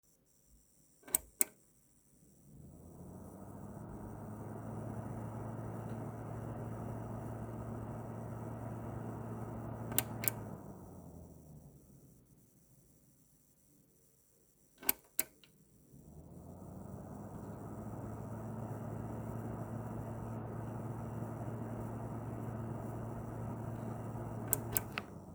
Schalte ich den Hybrid Modus aus, läuft der Lüfter durchgehend auf dem gleichen Lautstärke-Level, auch im Idle, ist für mich auch etwas mysteriös, da ich diese Probleme mit dem Vorgänger nicht hatte. Ich habe nachträglich mal eine Tonaufnahme angefügt, damit man sich darunter etwas vorstellen kann, hier aktiviere und deaktiviere ich den Hybrid Modus mehrmals unter Leerlauf, das Handy-Mic. halte ich dabei direkt an das Gehäuse wo das Netzteil sitzt.